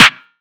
DDW4 SNARE 3.wav